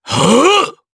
DarkKasel-Vox_Casting3_jp.wav